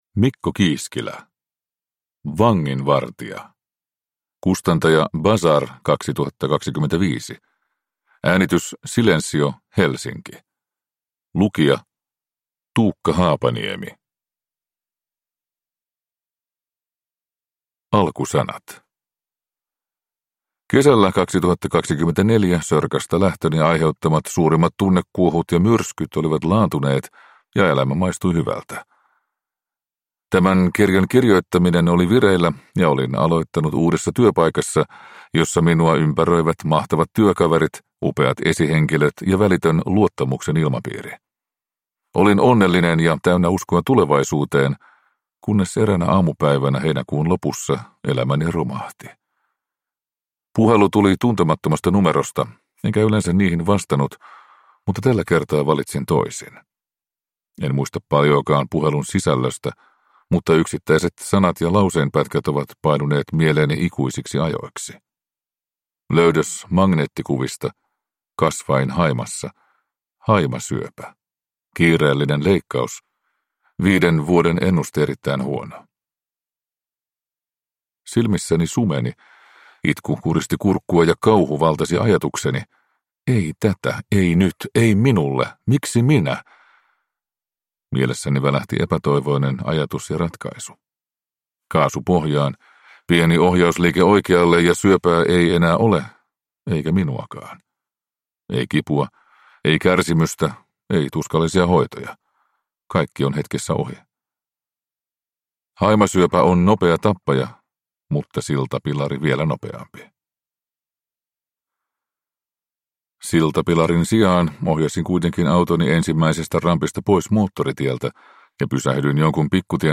Vanginvartija – Ljudbok